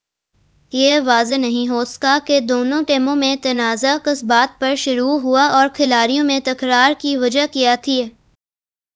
deepfake_detection_dataset_urdu / Spoofed_TTS /Speaker_01 /14.wav